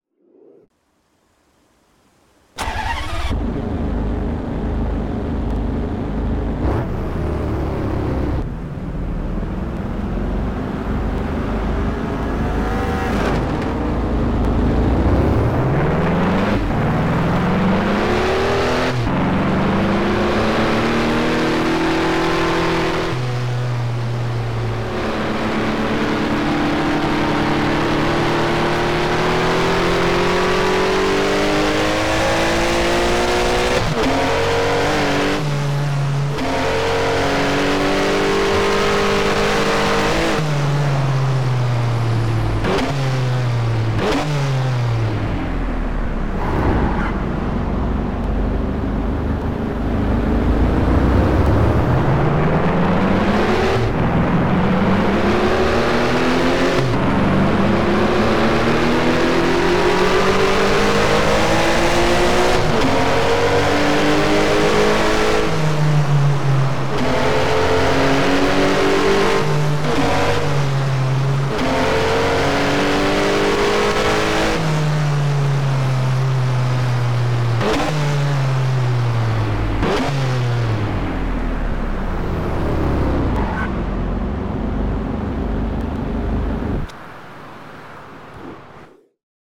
TDU 1 - Sound mods